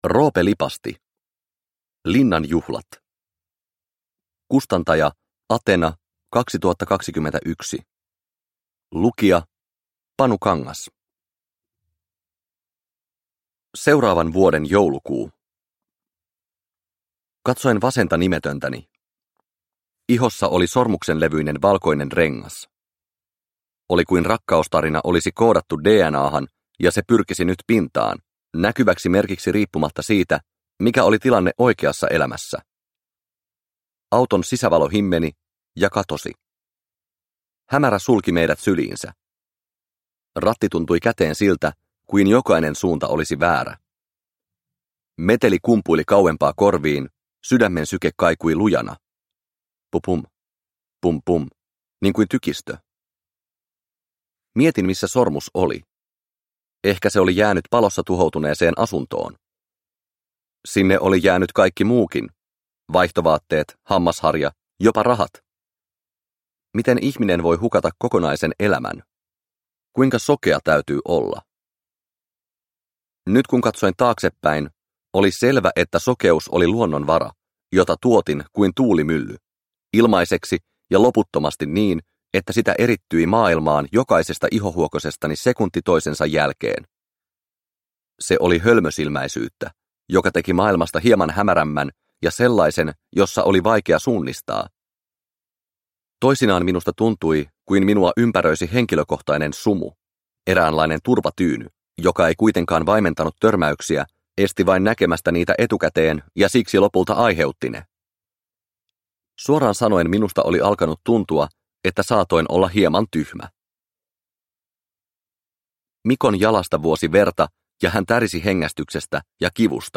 Linnan juhlat – Ljudbok – Laddas ner